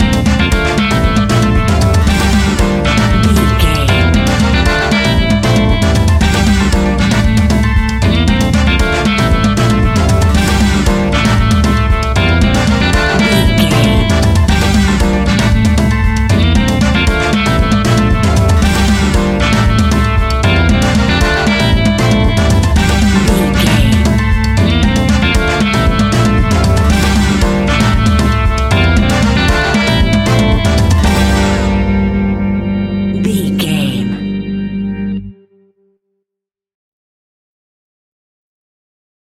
Aeolian/Minor
latin
salsa
uptempo
drums
bass guitar
percussion
saxophone
trumpet